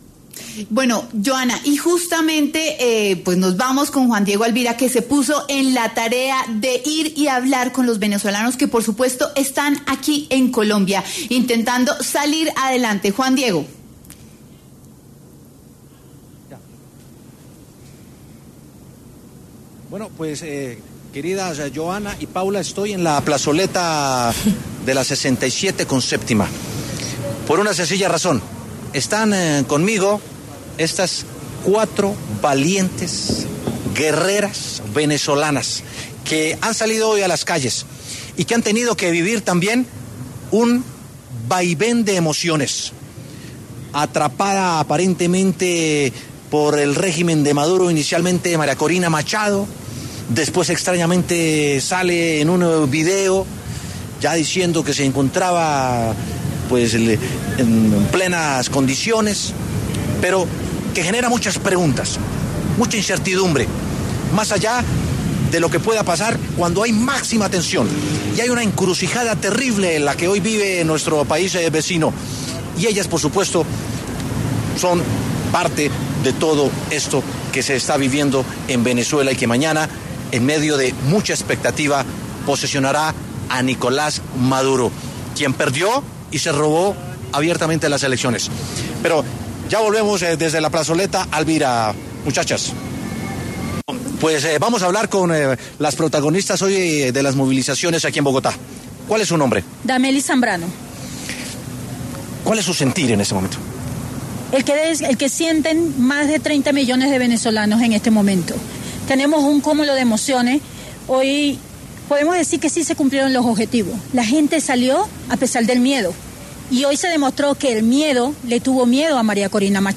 A propósito de esto, W Sin Carreta conversó con cuatro mujeres venezolanas que se encuentran en Bogotá y que rechazan el Gobierno de Maduro y su cuestionada reelección.